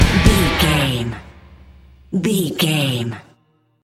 Aeolian/Minor
drums
electric guitar
bass guitar
hard rock
metal
angry
aggressive
energetic
intense
powerful
nu metal
alternative metal